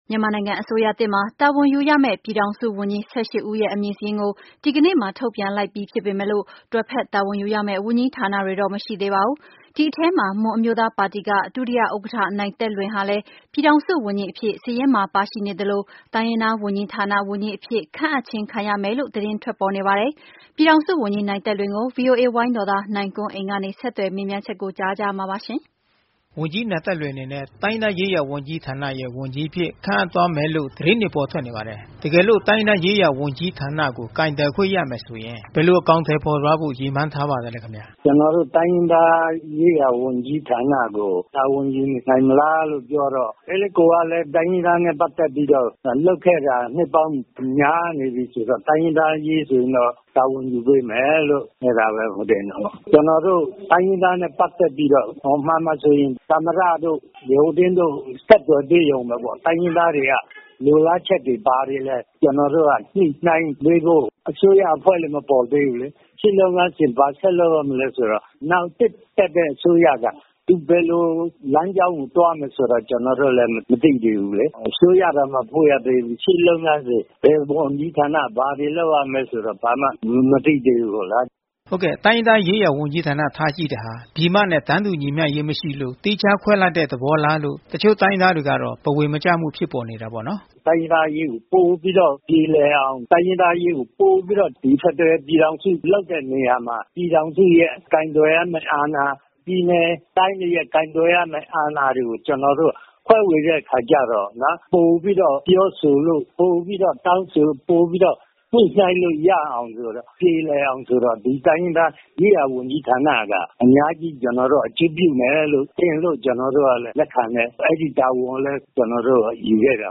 ပြည်ထောင်စုဝန်ကြီး အဆိုပြုခံရသူ နိုင်သက်လွင်နဲ့ မေးမြန်းခြင်း